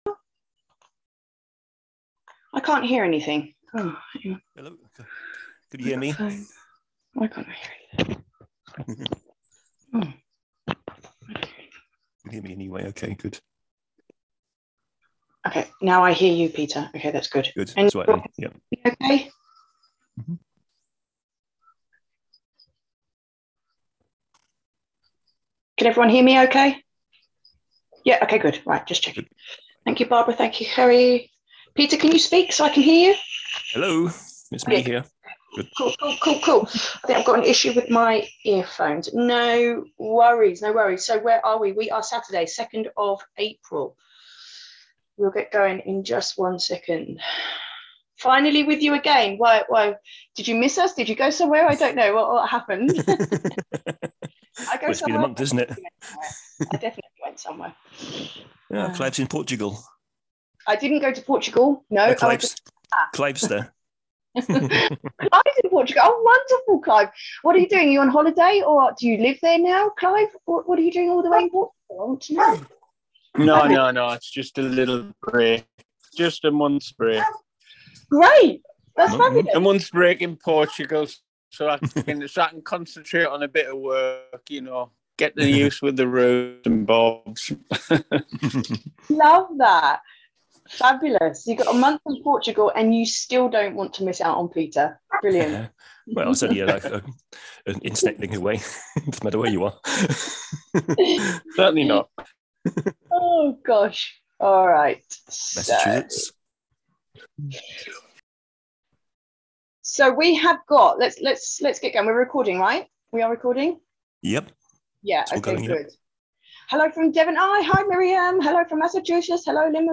Call Replays
This Q&A call focuses on the topic introduced on my recent article Reawakening Enthusiasm, Drive and Abundance